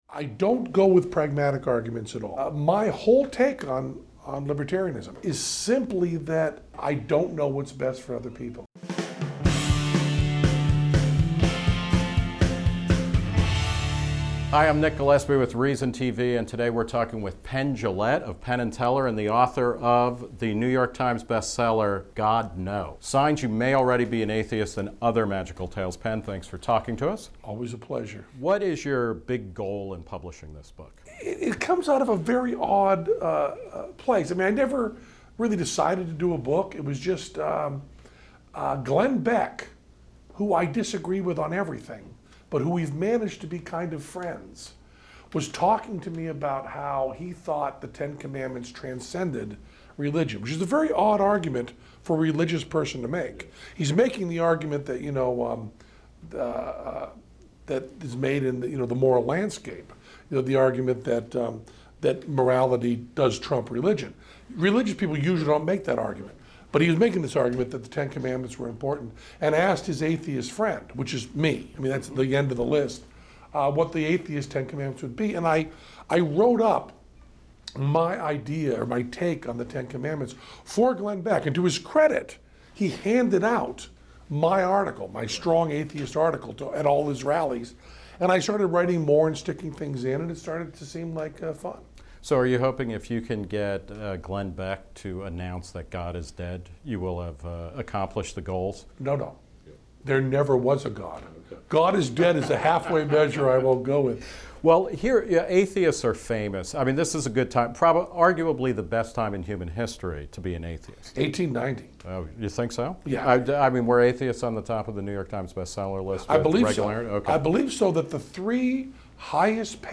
Reason's Nick Gillespie talks with the one-and-only Penn Jillette about his best-selling new book, God, No!: Signs You May Already Be an Atheist and Other Magical Tales, his friendship with Glenn Beck, skepticism versus cynicism, the role of religion in terrorism, why he's a libertarian, and much more in a wide-ranging conversation.